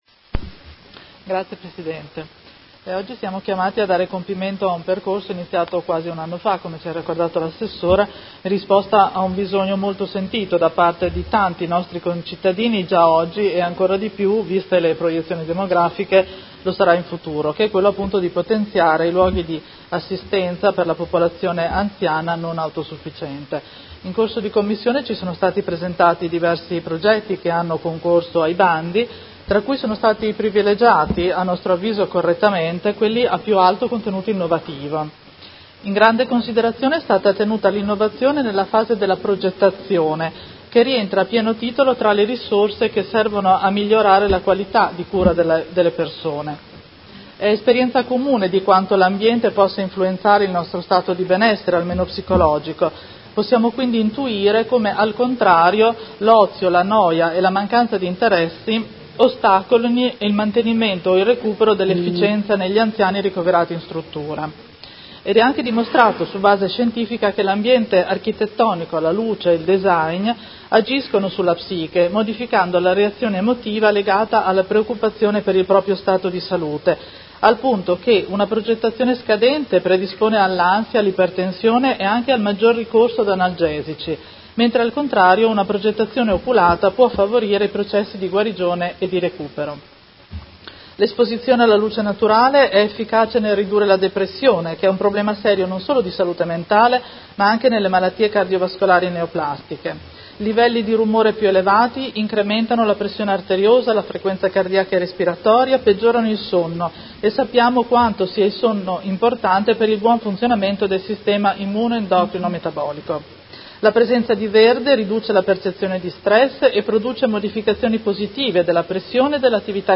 Chiara Pacchioni — Sito Audio Consiglio Comunale